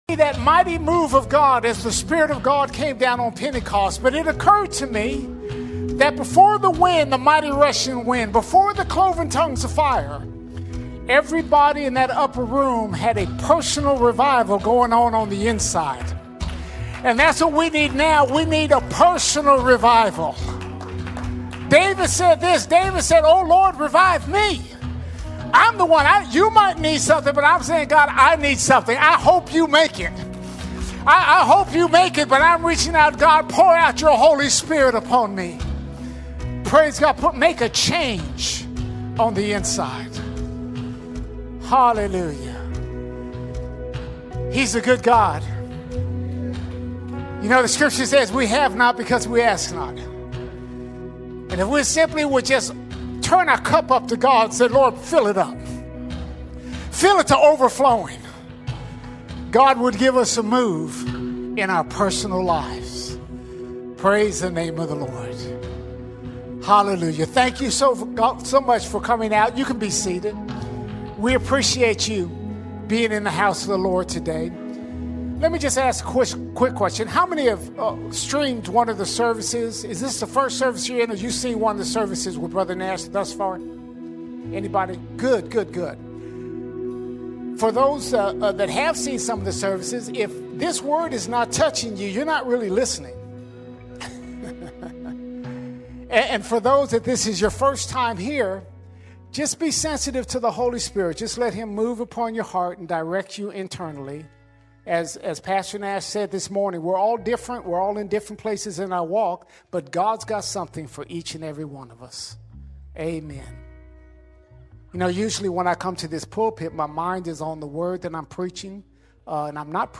23 February 2026 Series: Guest Speakers All Sermons What Do You Have In Your House What Do You Have In Your House Pressure has a way of making us look outward for solutions.